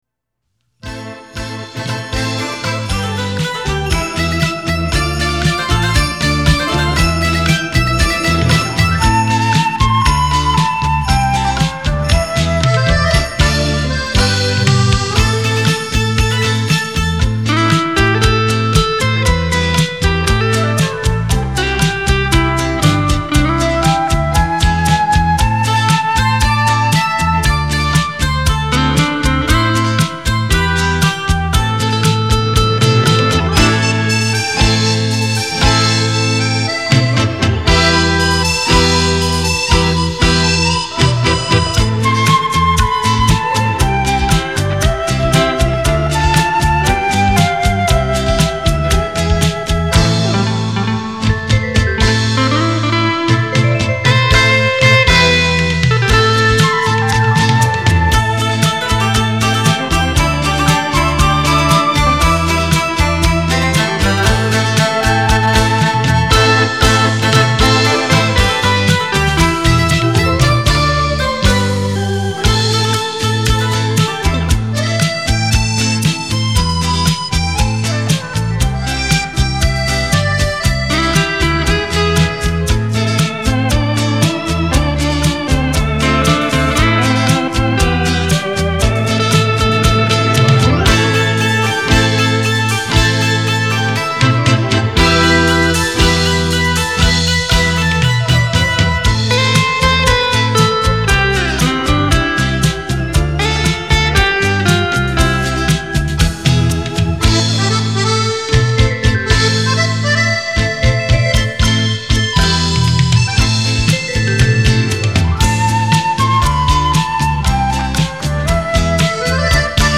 惬意轻松的专辑系列，感谢版主分享！！！